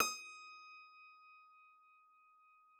53j-pno19-D4.wav